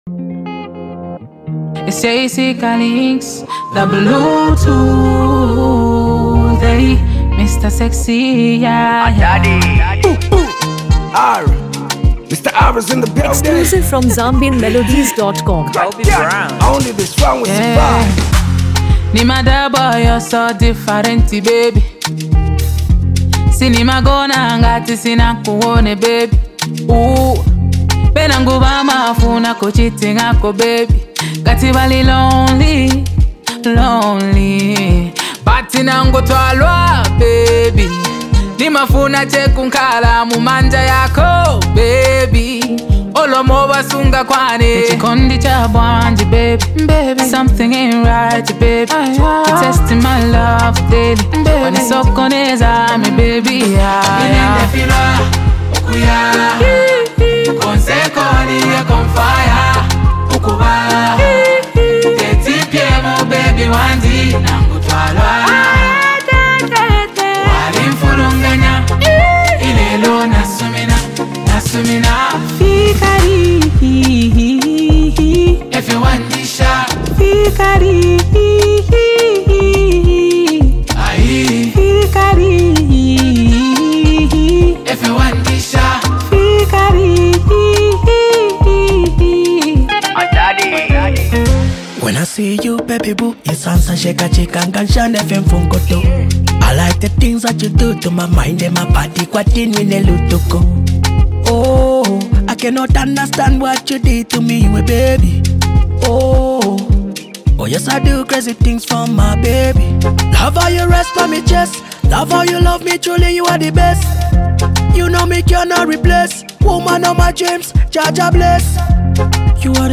Lusaka Trending Hip-Hop Hit
Genre: Afro-beats RnB